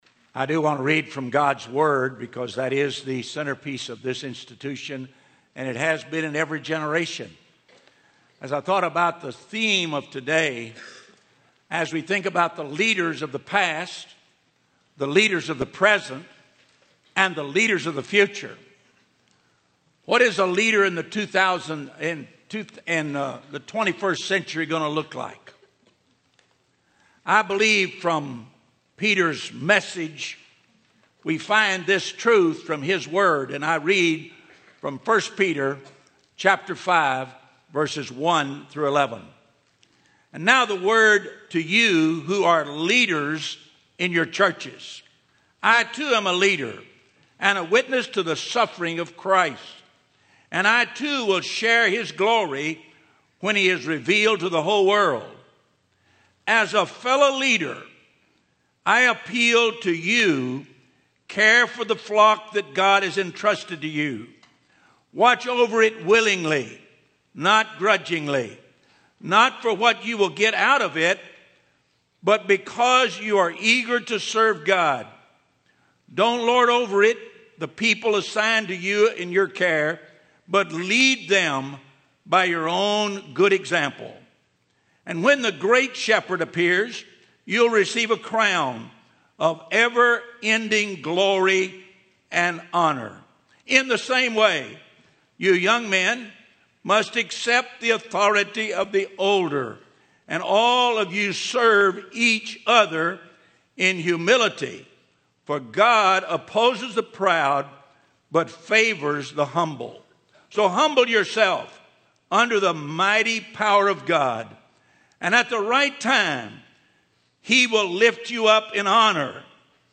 Homecoming Chapel